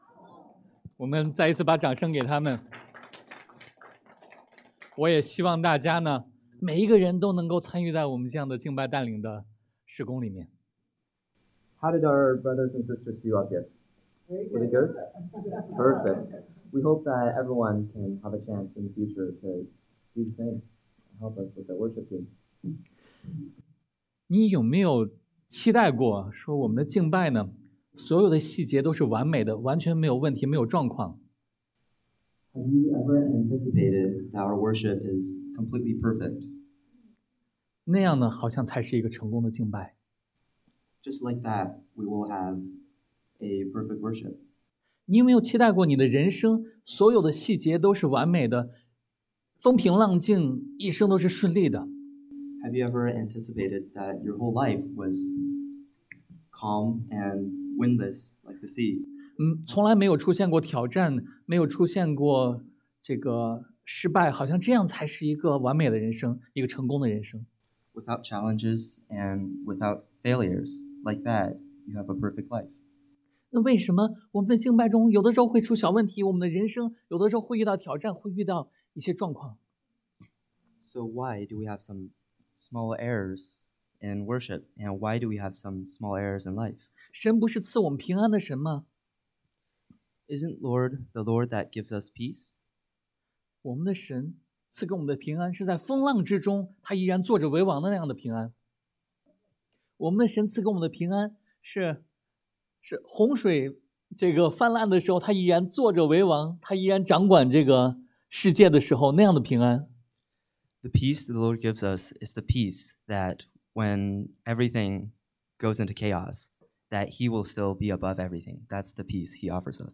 Service Type: Sunday AM 在黑暗中信靠神的主权 Trusting God’s Sovereignty in Darkness